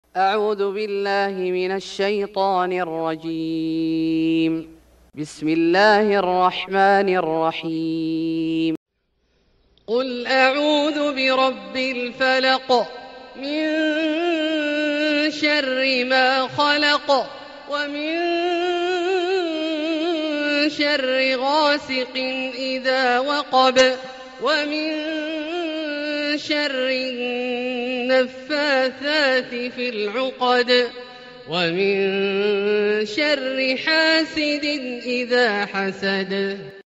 سورة الفلق Surat Al-Falaq > مصحف الشيخ عبدالله الجهني من الحرم المكي > المصحف - تلاوات الحرمين